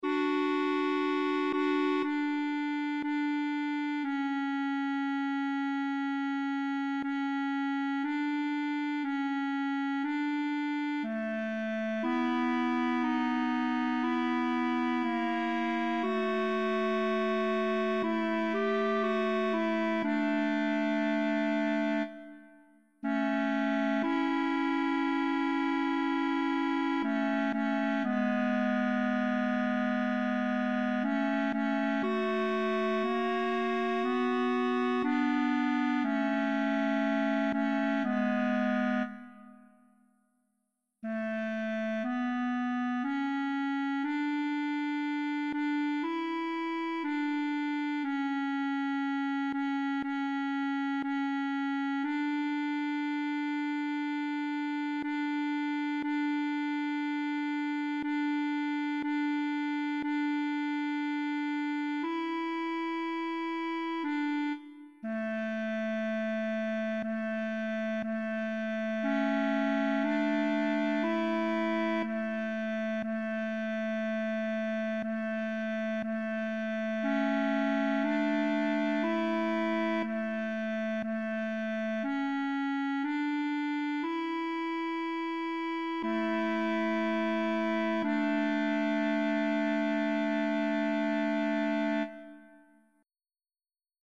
Partitura, cor mixt (pdf): Ca pe Împăratul
Voci (mp3): sopran, alto,
tenor, bas, cor mixt